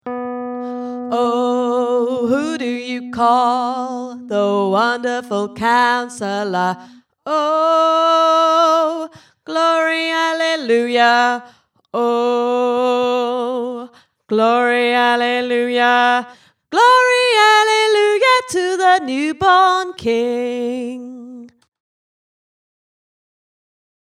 wonderful-counsellor-bass-octave-up.mp3